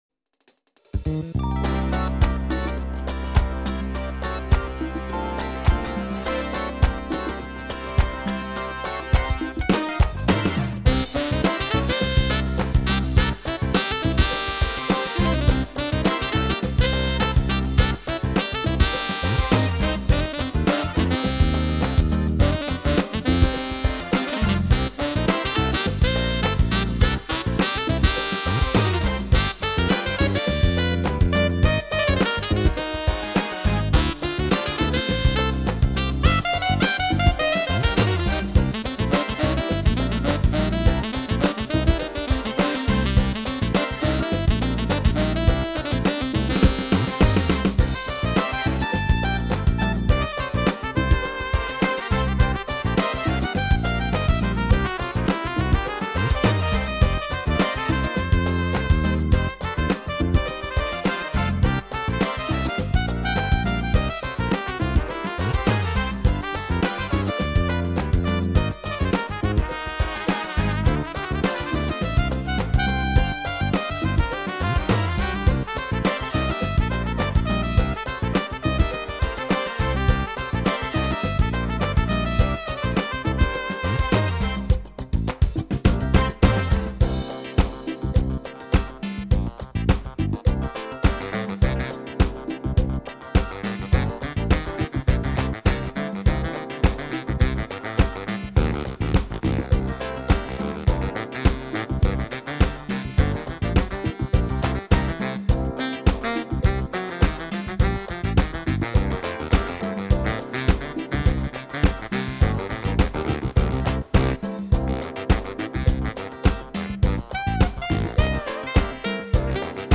So in 2004 I bought a Yamaha PSR3000.
The first one is a clavinet, the second one a synth-guitar, the third the baritonesax and the last one a saxophone section.